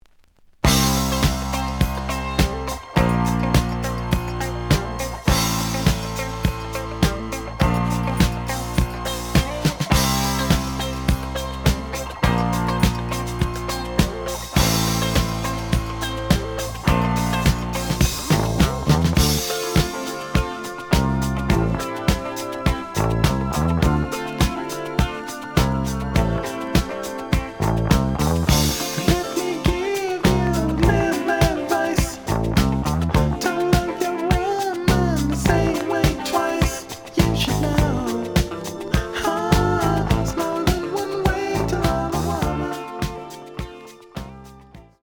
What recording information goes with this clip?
The audio sample is recorded from the actual item. Slight edge warp. But doesn't affect playing. Plays good.)